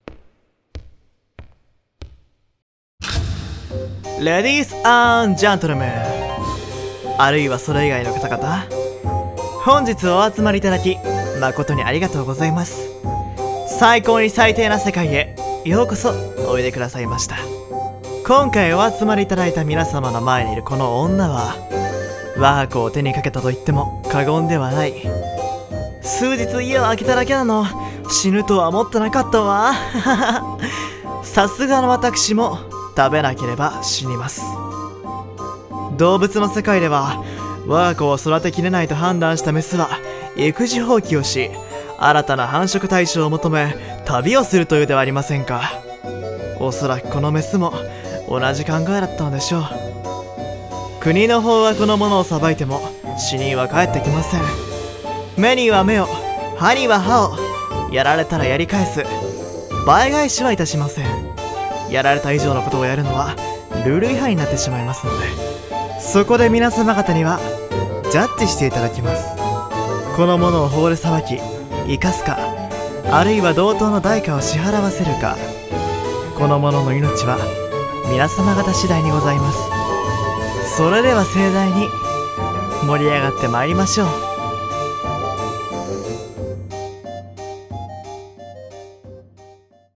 【声劇】ジャッジ